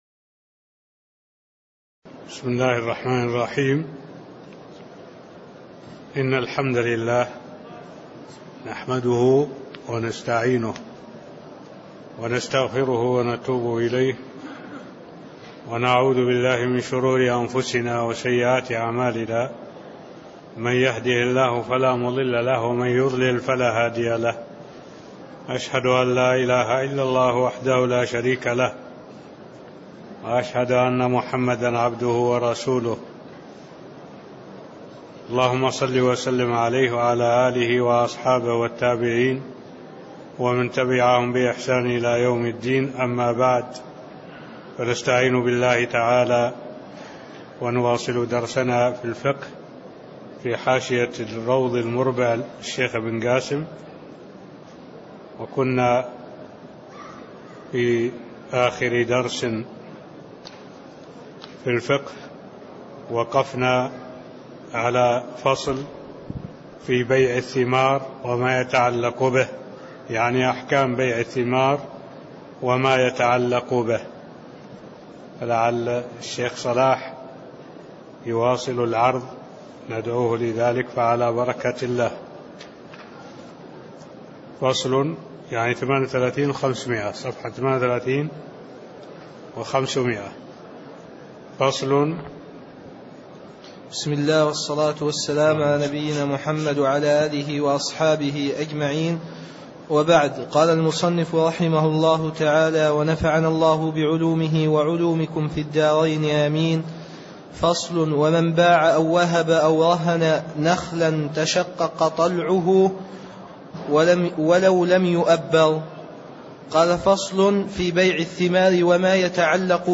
المكان: المسجد النبوي الشيخ: معالي الشيخ الدكتور صالح بن عبد الله العبود معالي الشيخ الدكتور صالح بن عبد الله العبود فصل قوله: (ومن باع أو وهب أو رهن نخلا تشقق طلعه) (03)